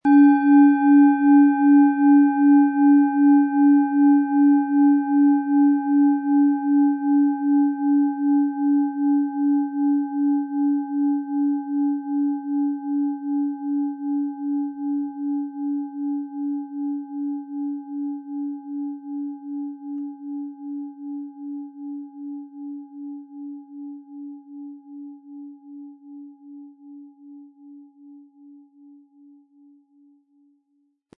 Planetenschale® In Fluß kommen & Ziele erreichen mit Mars, Ø 14,7 cm, 500-600 Gramm inkl. Klöppel
Planetenton 1
Es ist eine Planetenklangschale Mars aus einem kleinen Meisterbetrieb in Asien.
Im Audio-Player - Jetzt reinhören hören Sie genau den Original-Klang der angebotenen Schale. Wir haben versucht den Ton so authentisch wie machbar aufzunehmen, damit Sie gut wahrnehmen können, wie die Klangschale klingen wird.
MaterialBronze